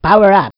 voice_power_up.wav